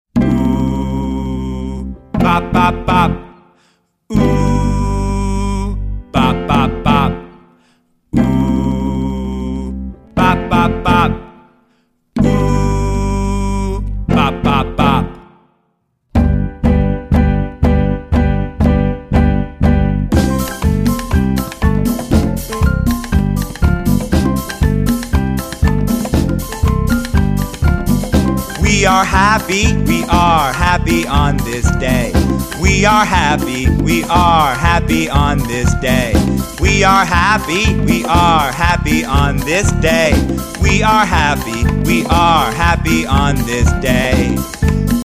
. it’s all there in its multi-instrumental glory.